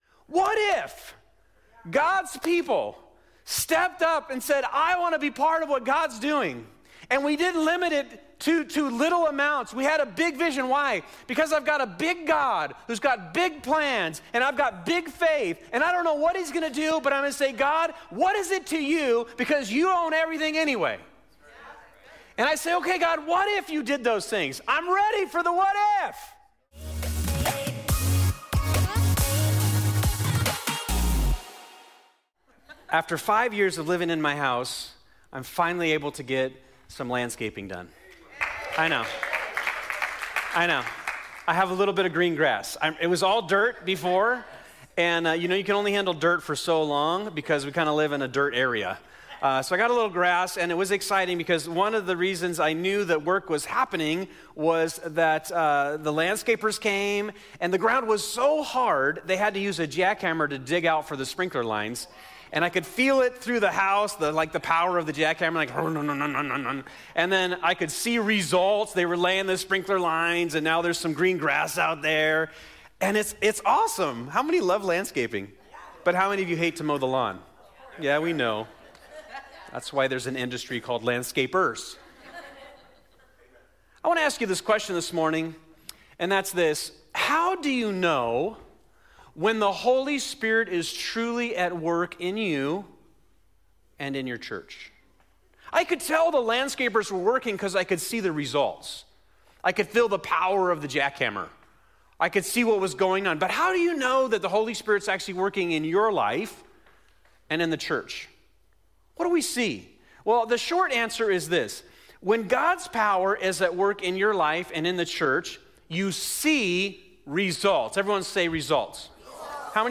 2025 The After Party Generosity Unity Sunday Morning "The After Party" is our series at Fusion Christian Church on the book of Acts.